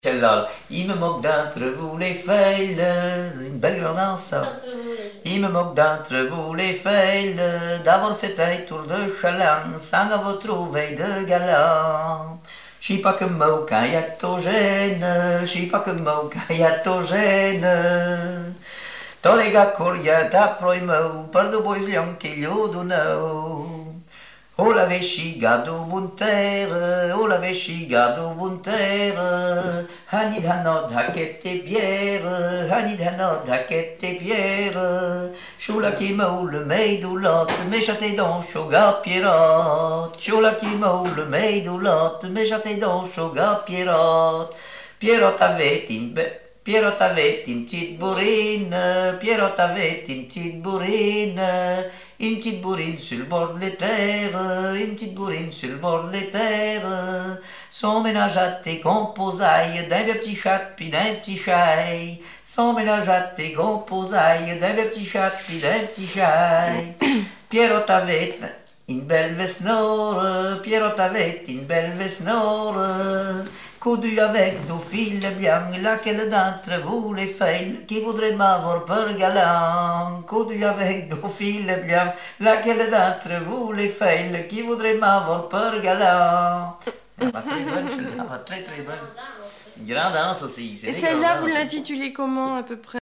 danse : ronde : grand'danse
Répertoire de chansons traditionnelles et populaires
Pièce musicale inédite